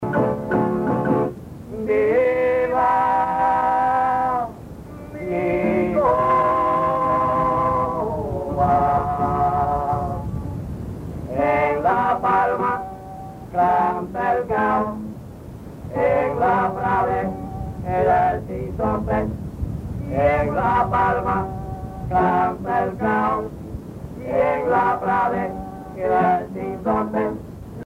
Parranda de Los Hermanos Sobrino
Pièces musicales tirées de la Parranda Tipica Espirituana, Sancti Spiritus, Cuba
Pièce musicale inédite